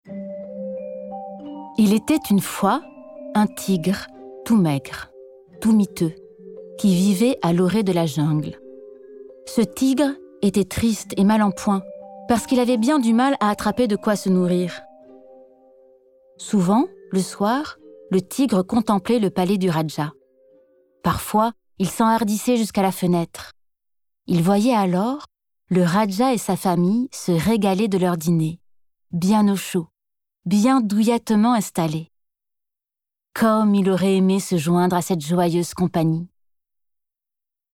Voix off
Conte